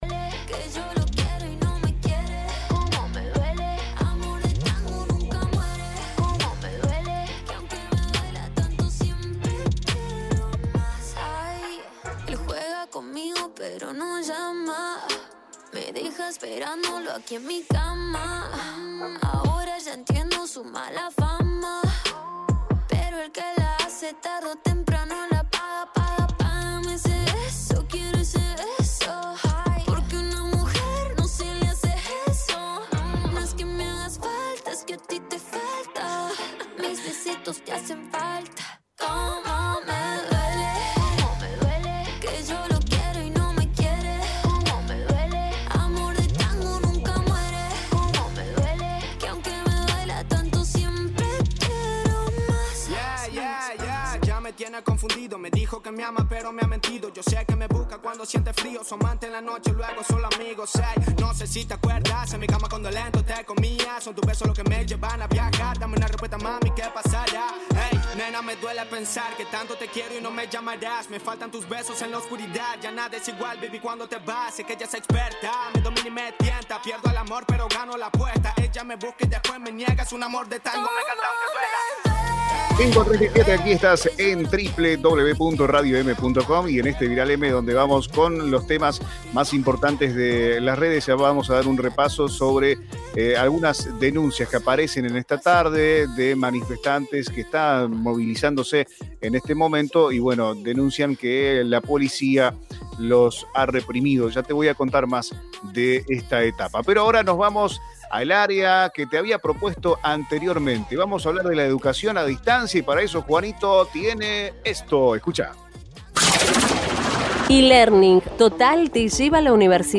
En diálogo al aire